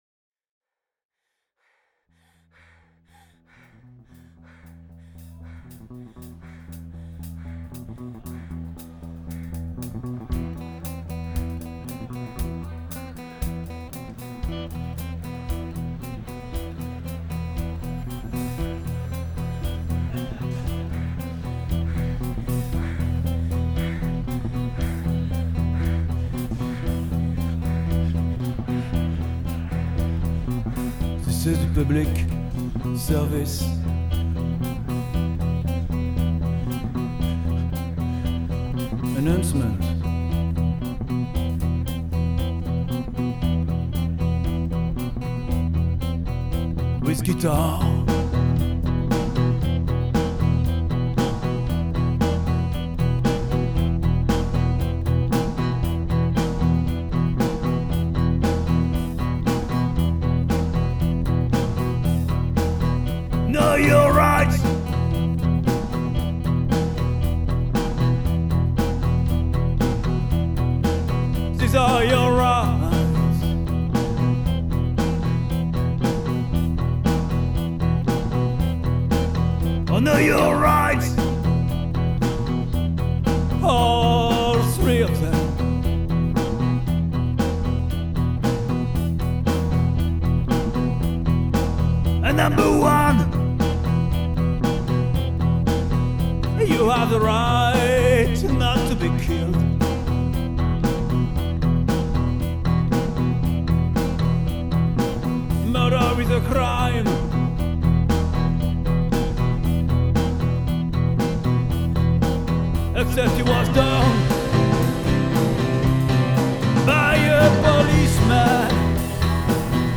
version électroacoustique